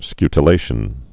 (skytl-āshən)